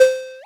edm-perc-24.wav